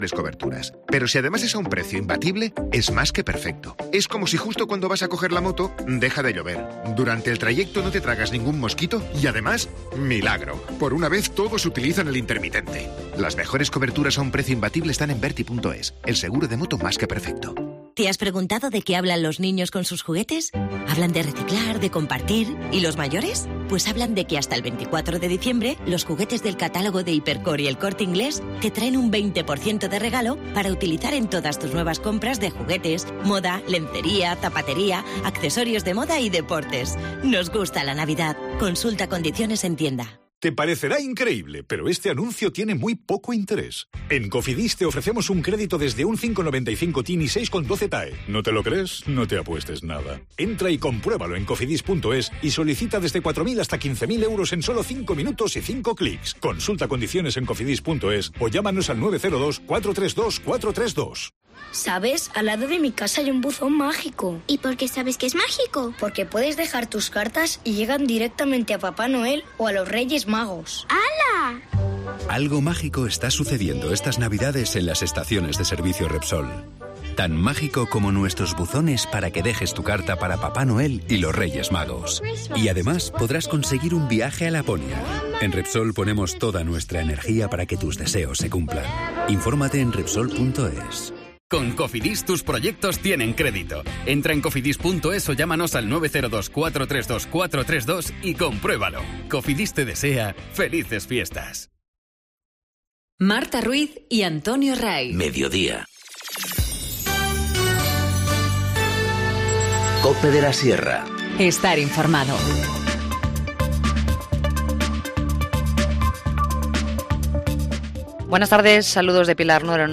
Informativo Mediodía 20 dic- 14:20h